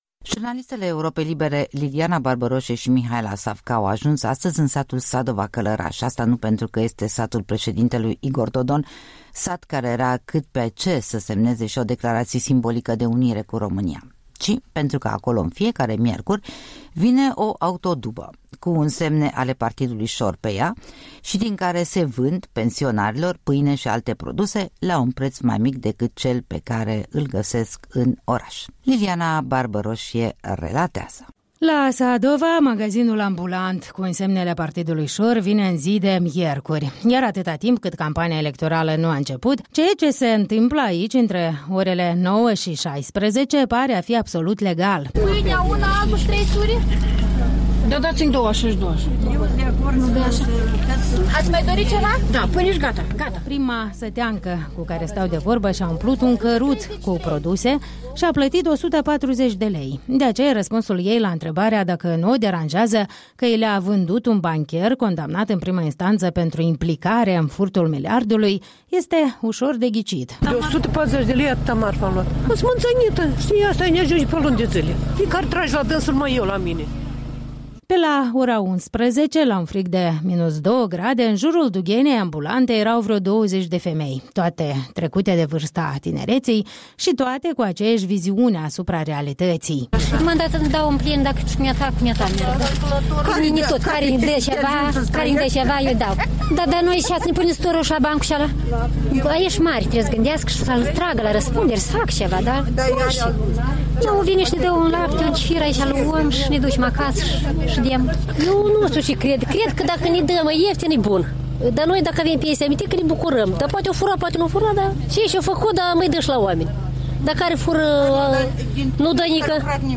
Un reportaj realizat la Sadova